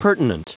Prononciation du mot pertinent en anglais (fichier audio)
Prononciation du mot : pertinent